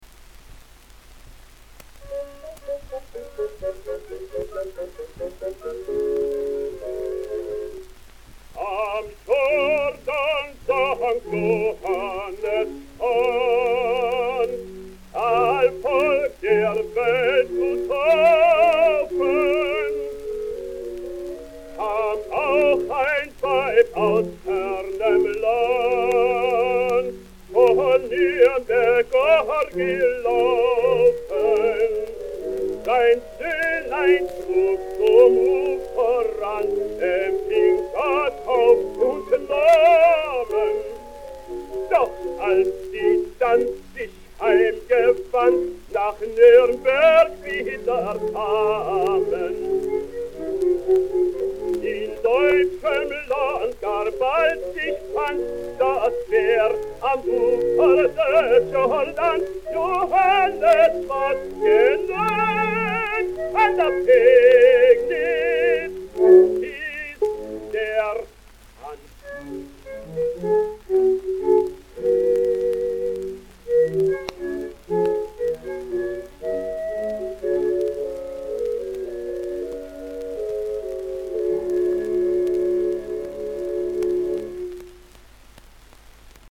He made his debut in 1895 as Gomez (Das Nachtlager in Granada by Conradin Kreutzer) in Wrocław/Breslau. 1896 to 1900, he was a lyrical tenor at the opera in Köln, where he switched to the buffo repertoire.